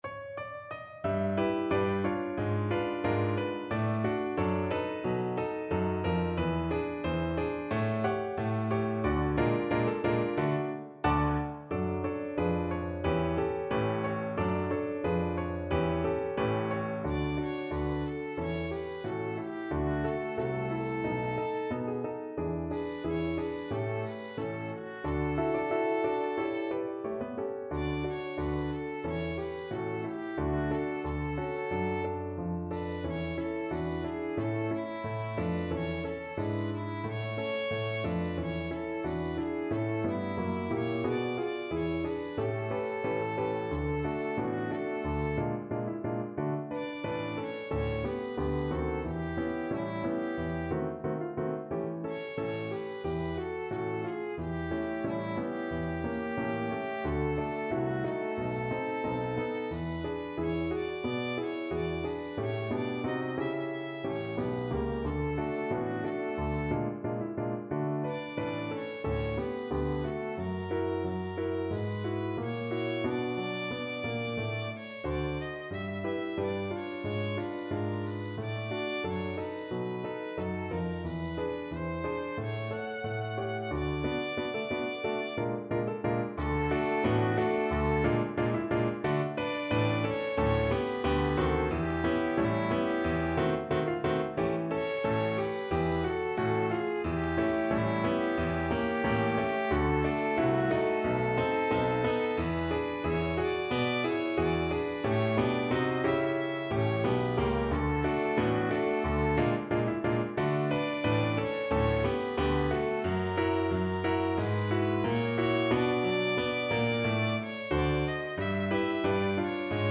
2/2 (View more 2/2 Music)
~ = 180 Moderato
Pop (View more Pop Violin Music)